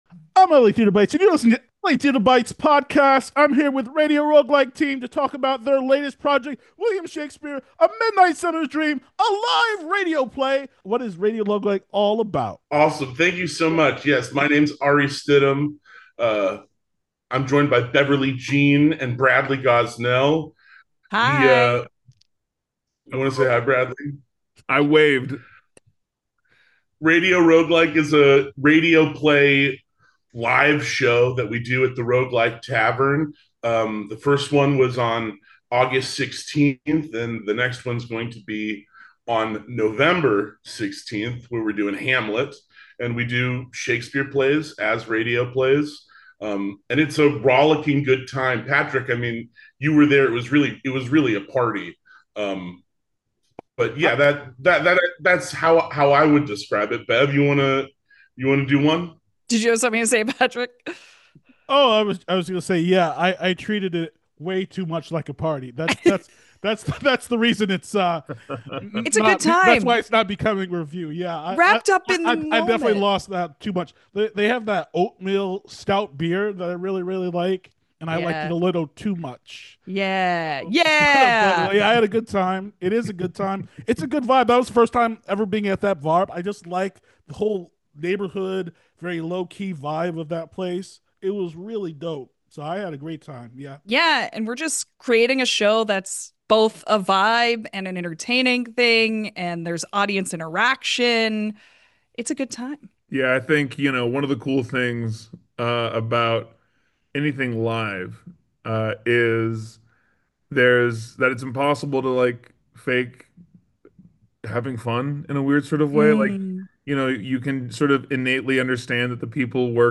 Interview with Radio Roguelike Team about Shakespeare Radio Plays
Interview with Radio Roguelike Team after their latest bar Shakespeare performance of A Midsummer Night’s Dream.